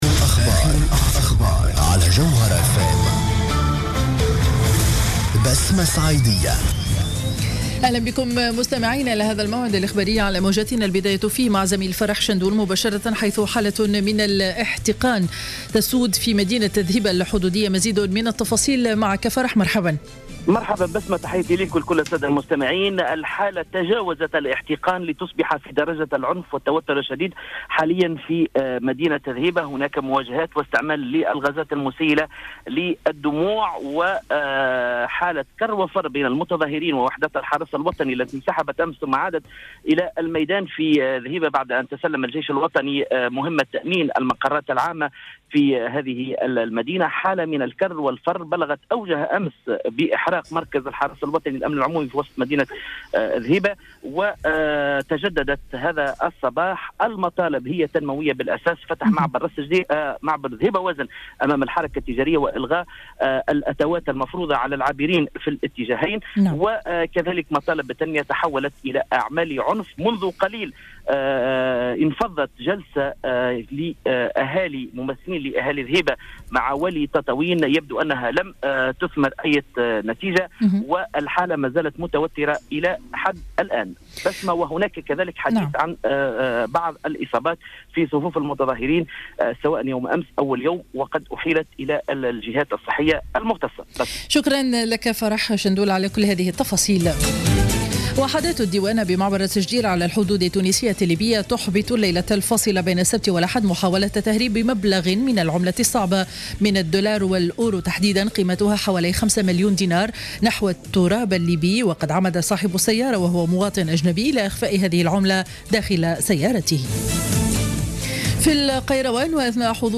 نشرة أخبار منتصف النهار ليوم الأحد 08-02-15